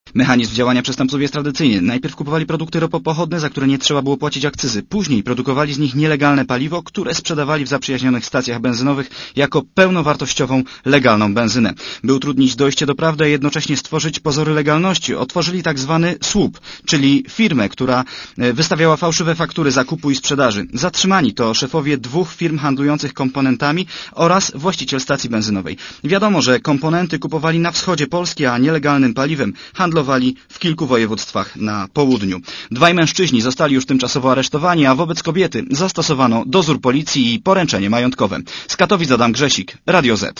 Źródło: Archiwum Relacja reportera Radia ZET Troje zatrzymanych to mieszkańcy Katowic i Wodzisławia Śląskiego w wieku od 34 do 49 lat.